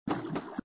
AV_footstep_runloop_water.ogg